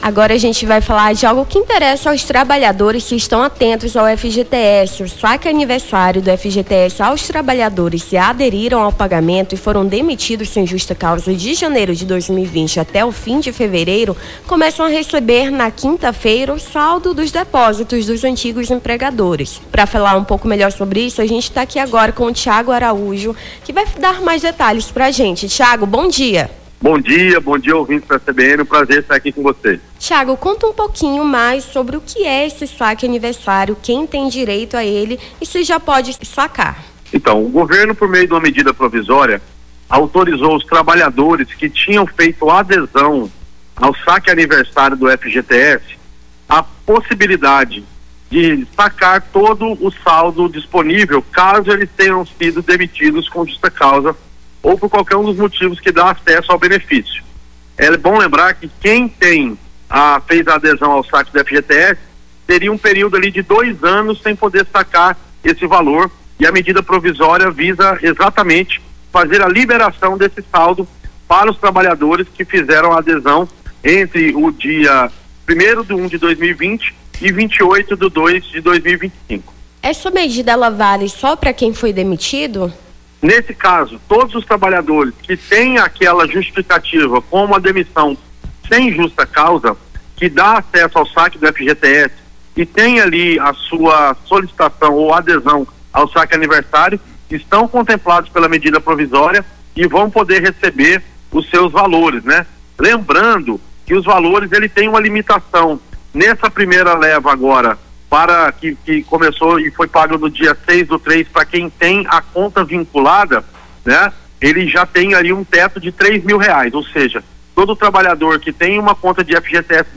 Nome do Artista - CENSURA - ENTREVISTA PAGAMENTO DO FGTS (07-03-25).mp3